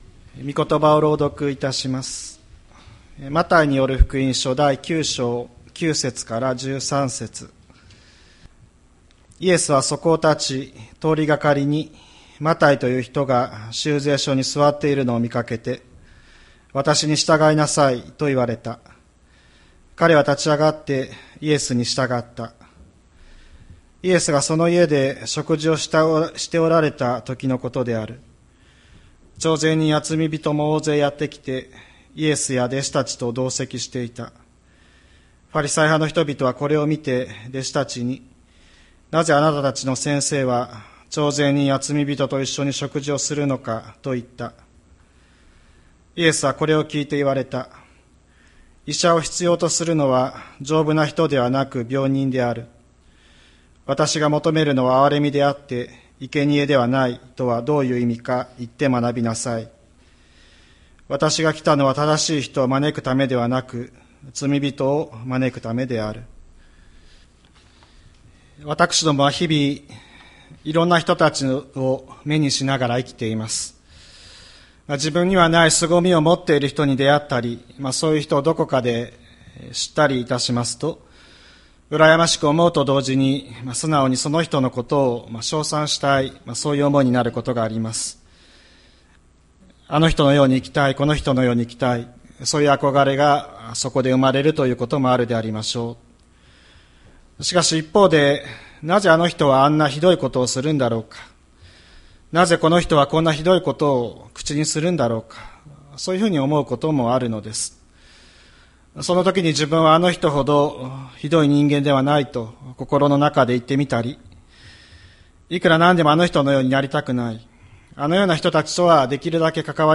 2024年08月18日朝の礼拝「神を愛して生きる」吹田市千里山のキリスト教会
千里山教会 2024年08月18日の礼拝メッセージ。